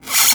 Index of /90_sSampleCDs/E-MU Producer Series Vol. 4 – Denny Jaeger Private/Effects/Scratch